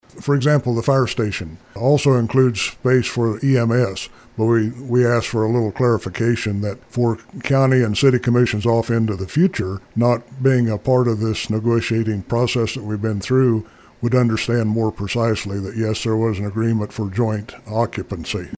The commissioners voted on whether or not to pass a county wide sales tax initiative to fund county and city projects commissioner Dave Jones on their decision.